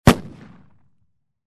Звуки хлопушки
Звук хлопка детской взрывной пушки